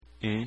Давайте прослушаем произношение этих звуков:
“em/en” (носовое “e”):